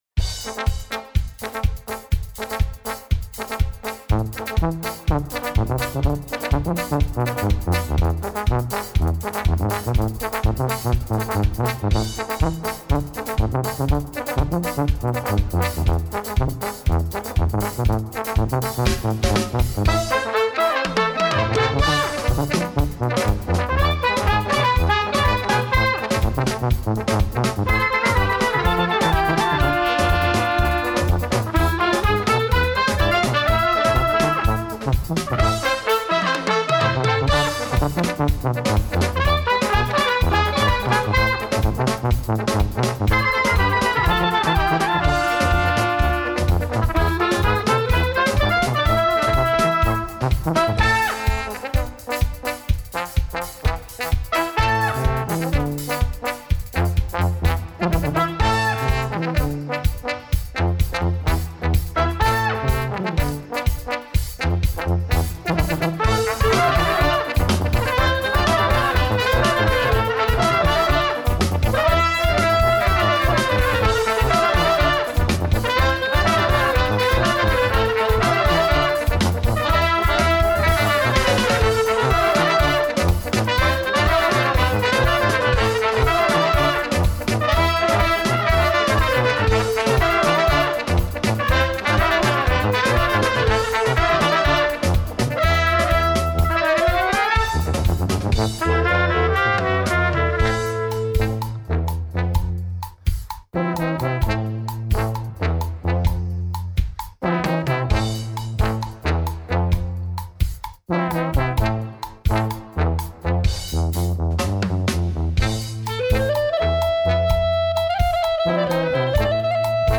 Radioreportaj
La eveniment a participat un numeros public.